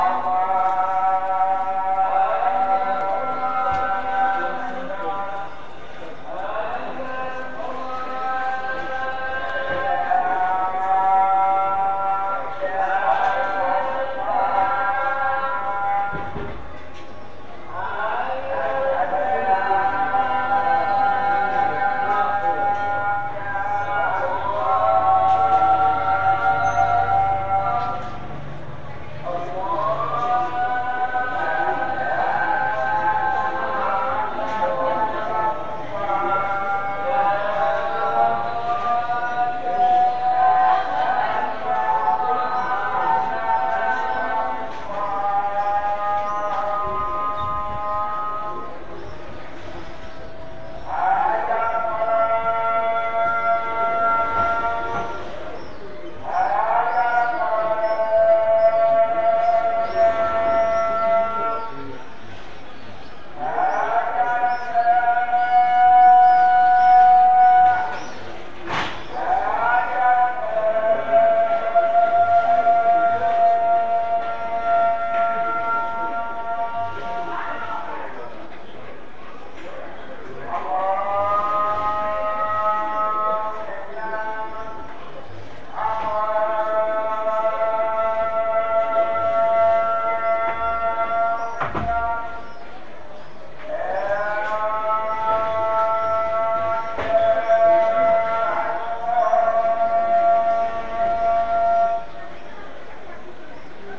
I recorded two audio clips from Rabat.
the second one you can hear what I and everyone else heard five times a day in every city in Morocco, the Islamic call to prayer.
rabat_call_to_prayer.ogg